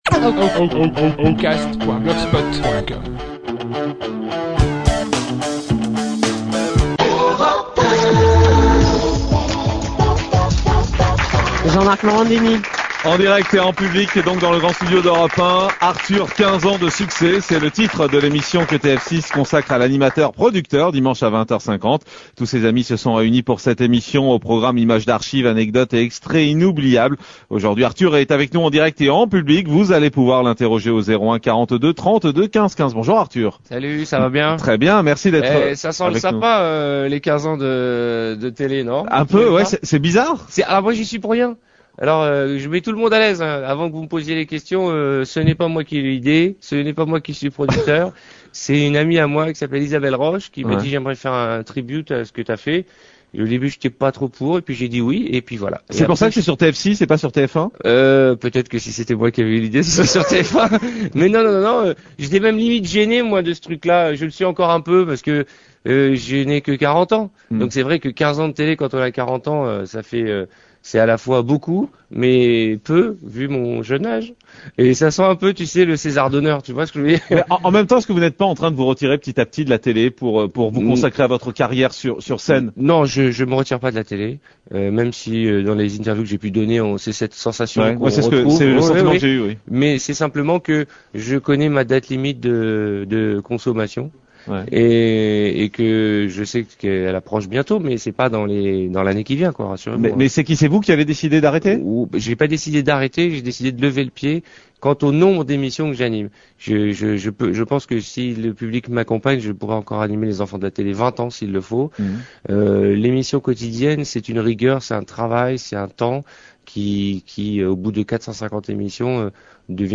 Morandini interview Arthur sur Europe 1